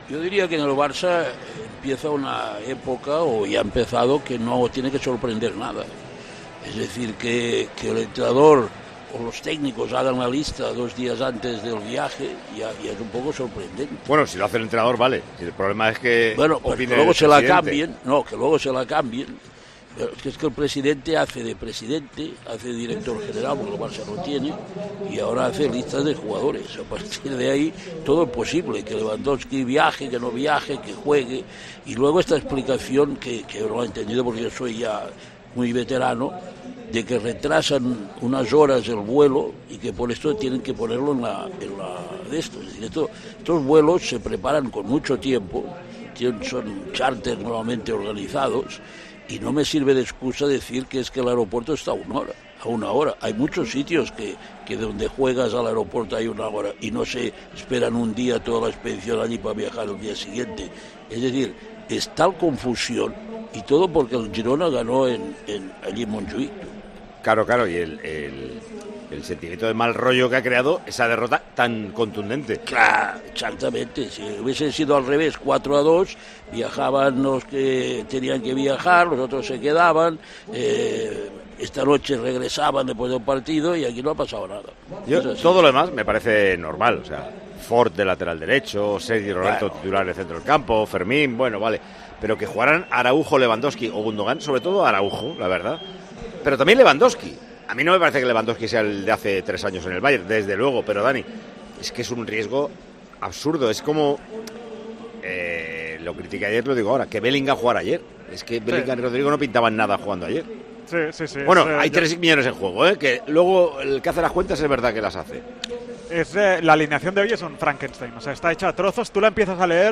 El director de Tiempo de Juego no entendía la decisión de Xavi Hernández, que pasó de no convocar a cuatro jugadores a alinear a uno en el once titular.